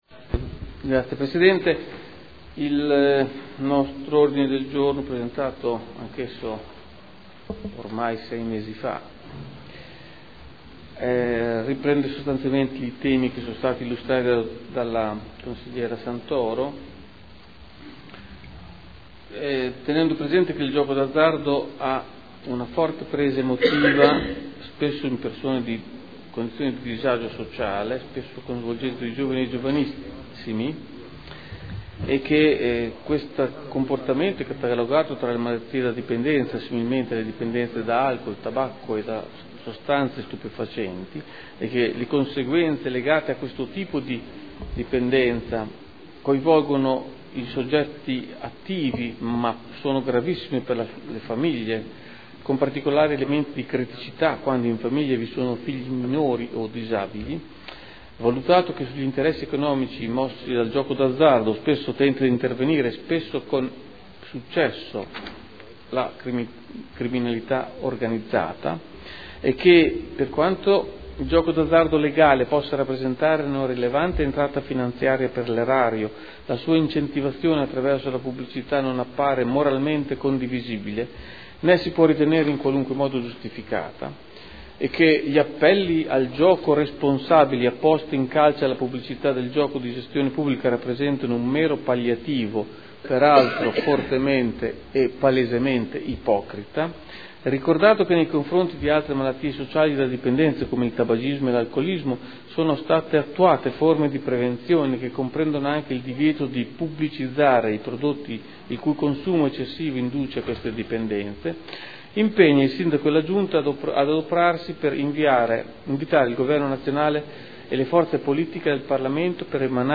Luigi Alberto Pini — Sito Audio Consiglio Comunale